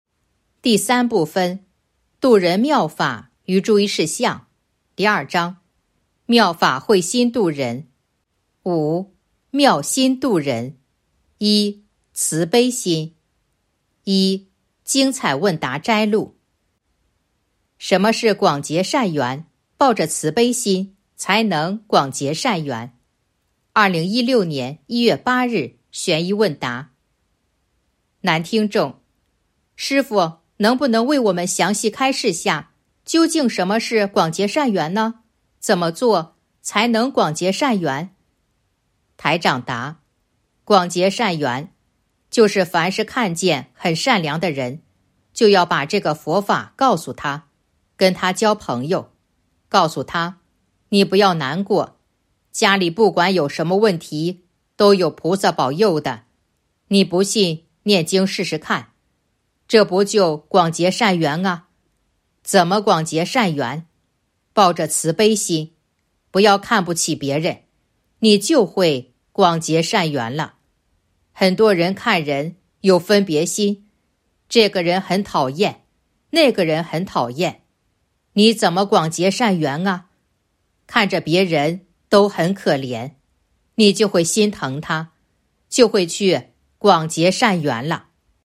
041.（一）慈悲心 1. 精彩问答摘录《弘法度人手册》【有声书】